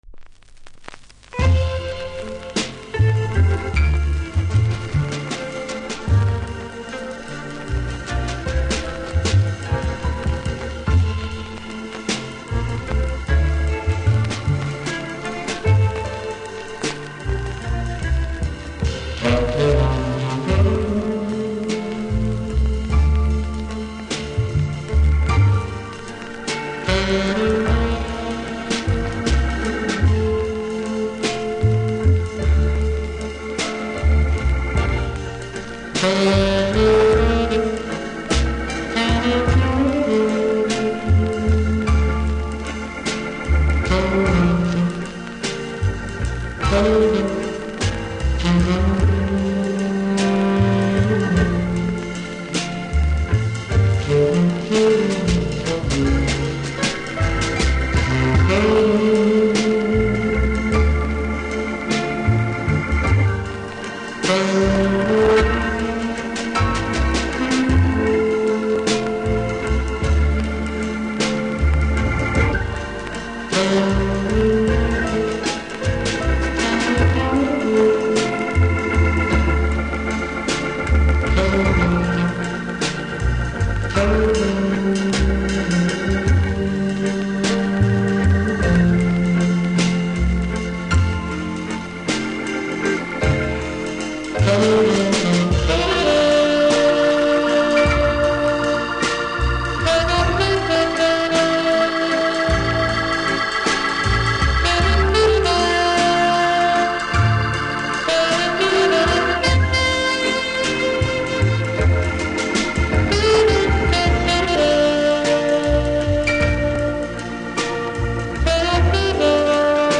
プレス起因なのか途中音悪い部分あり。